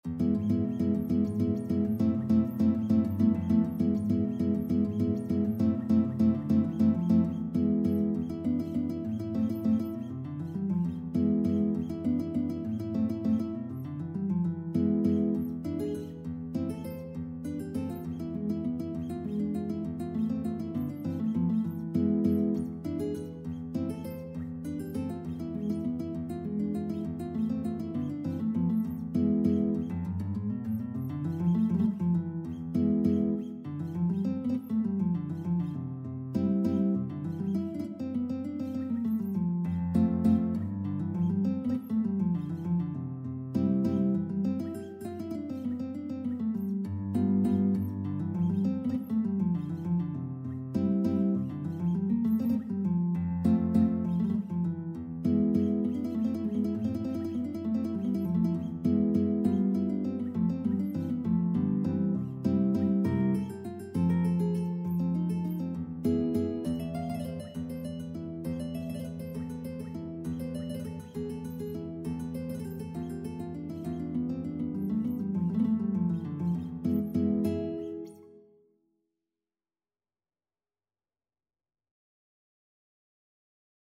Classical Tárrega, Francisco Malagueña fácil Guitar version
Guitar  (View more Intermediate Guitar Music)
Classical (View more Classical Guitar Music)